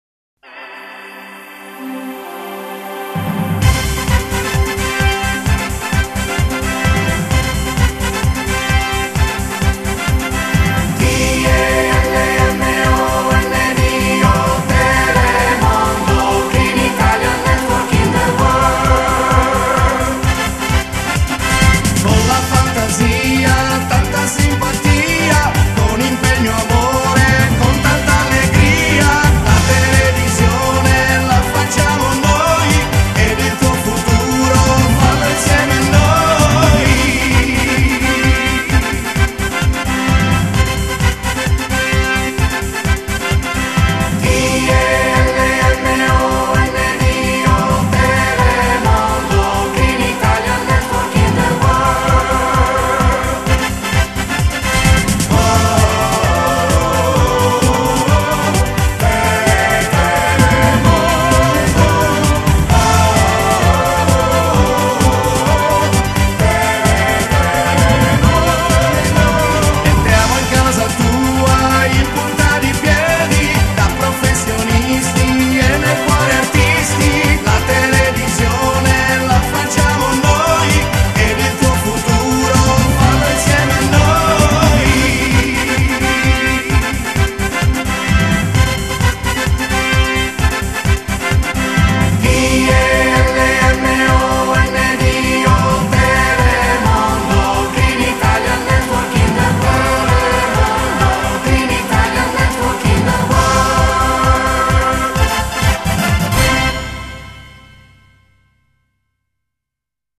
Genere: Electro 80 dance